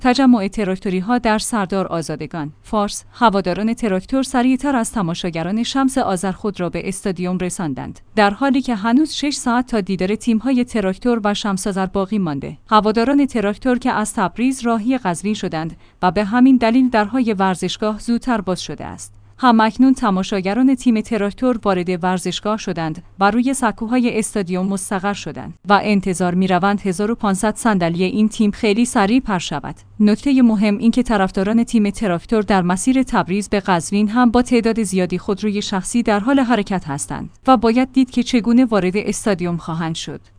تجمع تراکتوری‌ها در سردار آزادگان